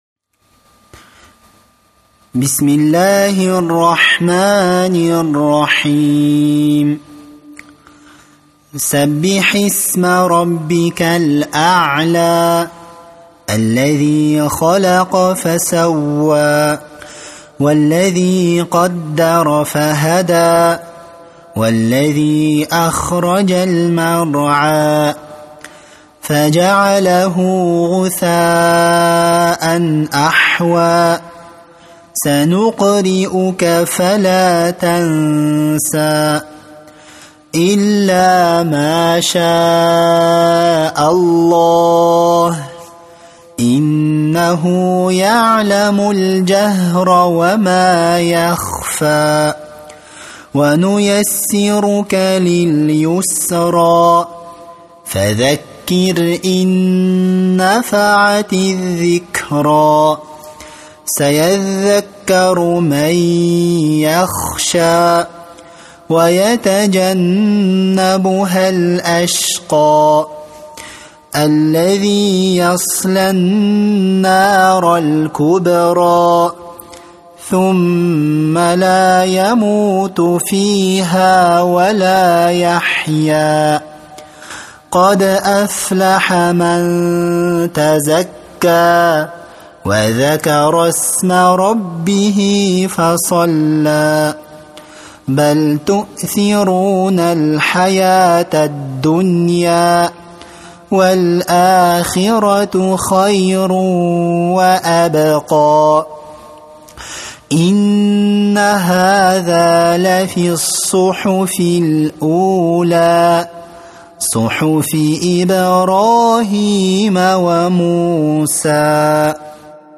Ассаляму алейкум уа рахматуЛлахи уа баракатух! маша Аллах, ариптери оте анык естиледи екен, жаттаганга оте ынгайлы))) Осы кисинин кырагатымен дугаларды да алсак нур устине нур болар еди, мумкин болар ма екен?